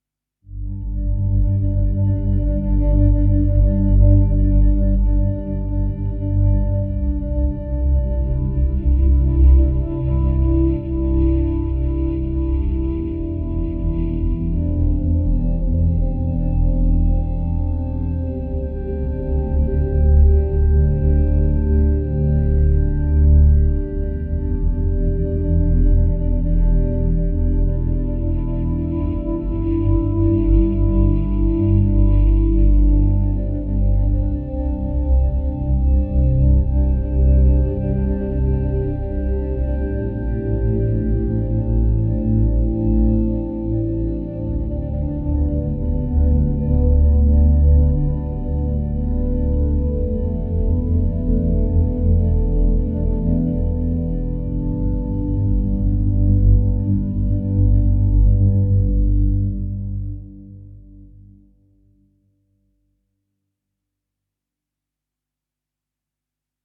bande originale de film
univers sonore profond